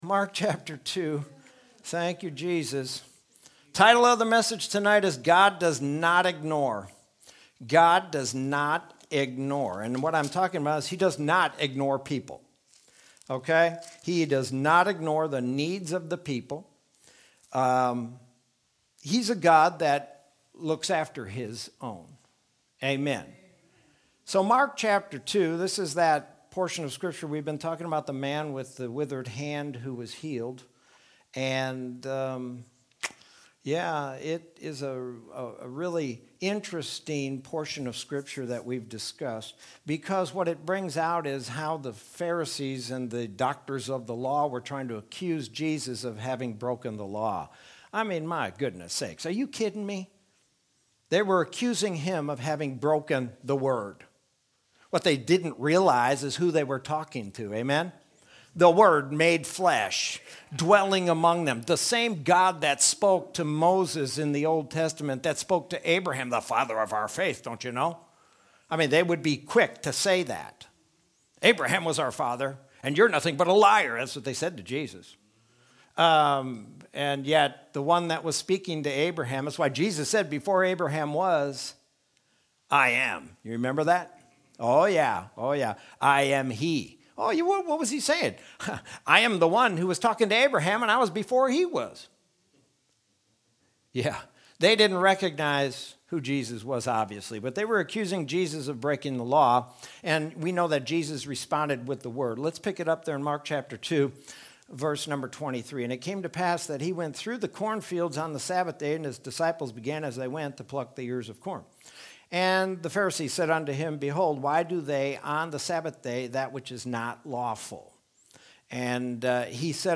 Sermon from Wednesday, April 21st, 2021.